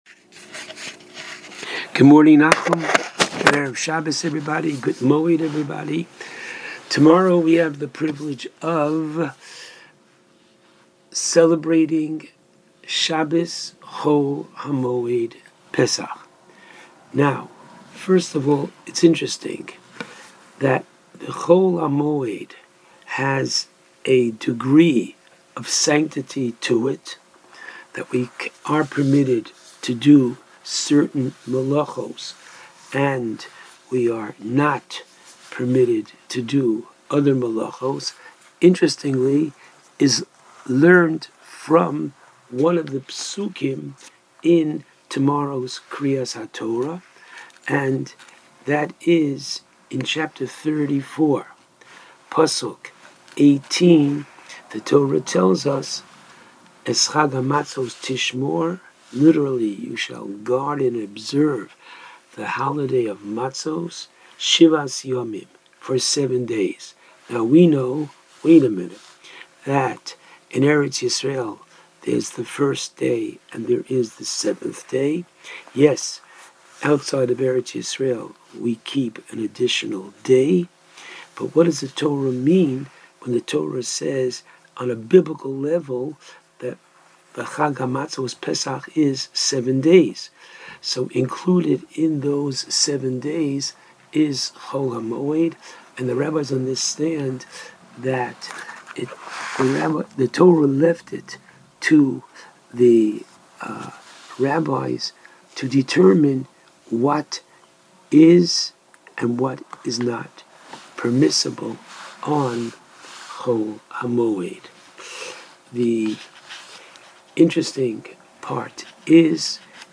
called in to JM in the AM to discuss Shabbos Chol Hamoed pesach.